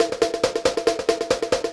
K-4 Percussion.wav